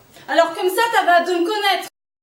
On entend ce que l'on voit